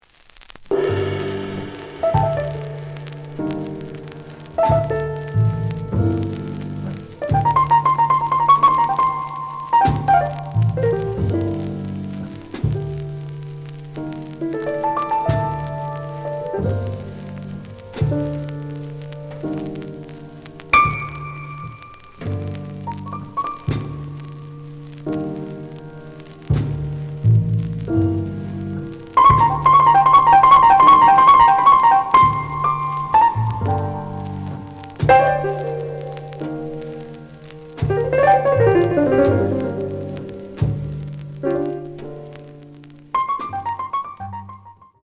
The audio sample is recorded from the actual item.
●Genre: Jazz Funk / Soul Jazz
Some noise on both sides.